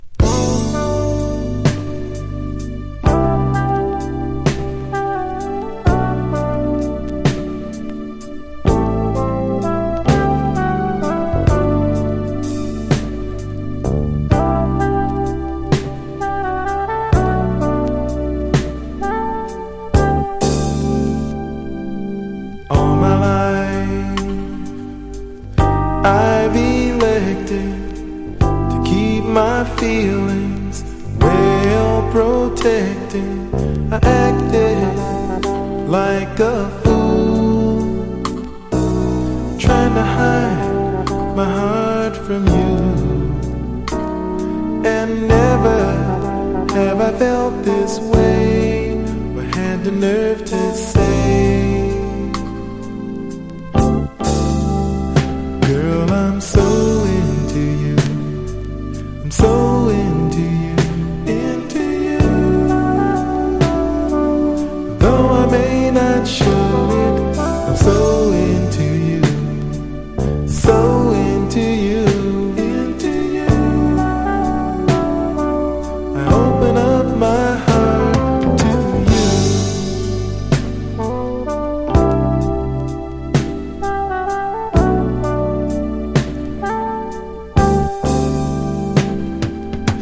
HIP HOPサンプリング・ネタにもなっている、スウィート・メロウ・クラシック！！